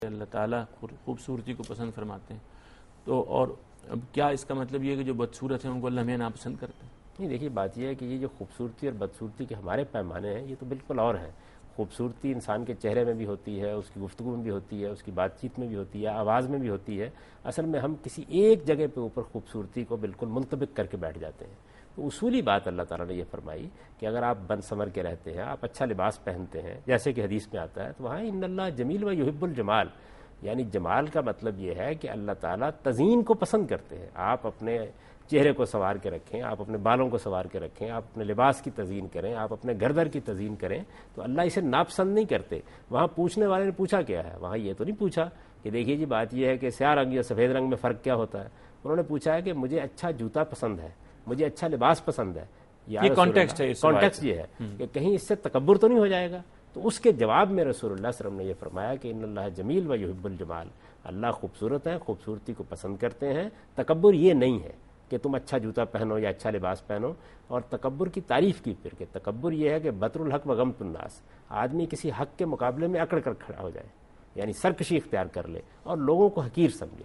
Answer to a Question by Javed Ahmad Ghamidi during a talk show "Deen o Danish" on Duny News TV